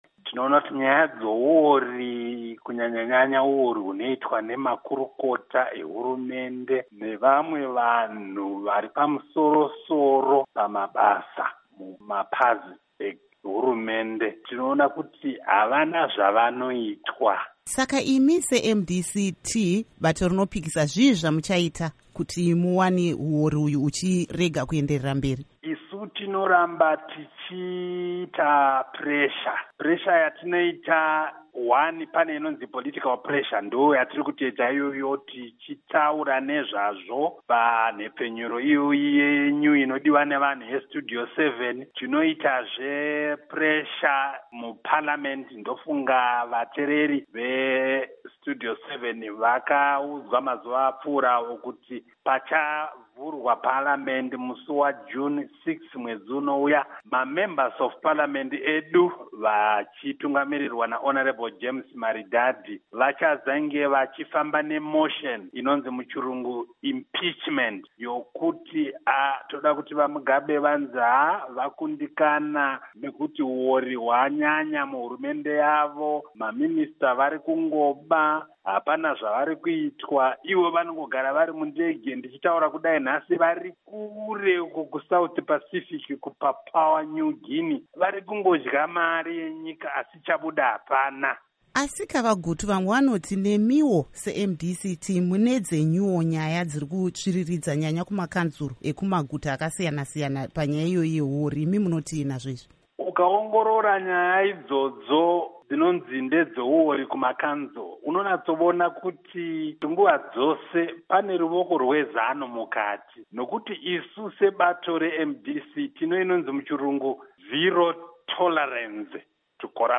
Hurukuro NaVa Obert Gutu